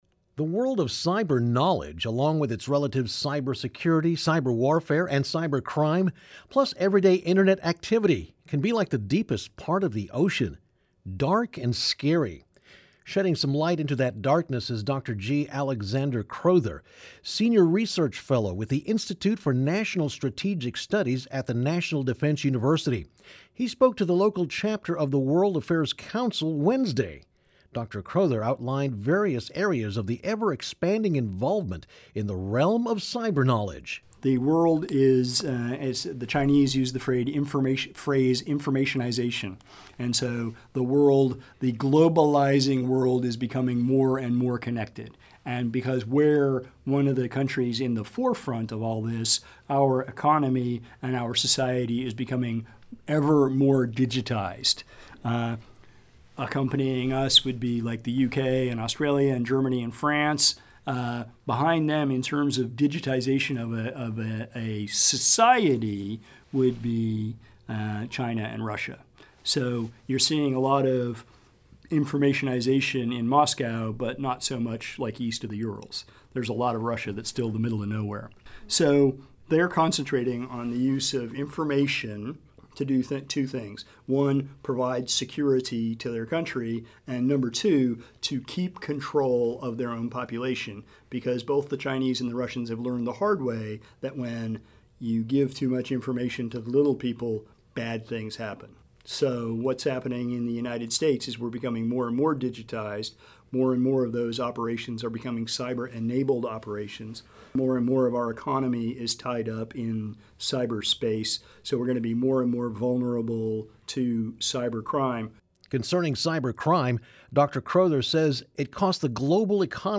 Cyber warfare expert – interview
Interview